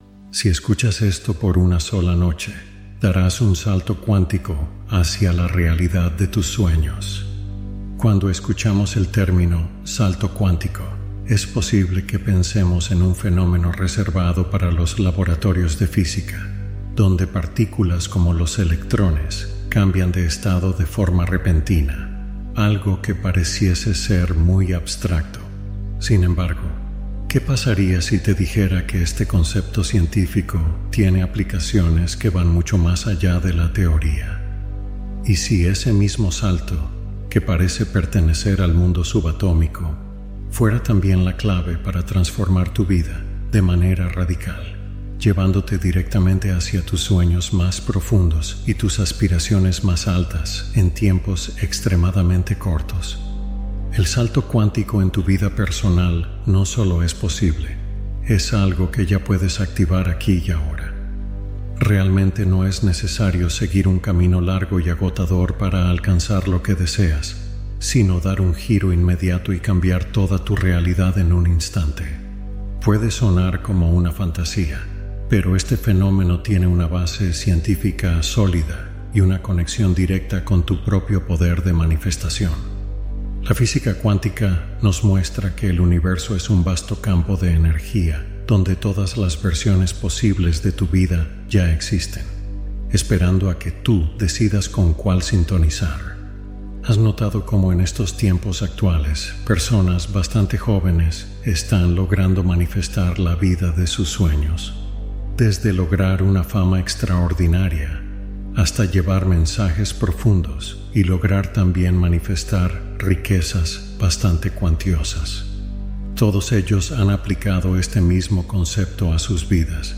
Guía meditativa inspirada en procesos de cambio cuántico interno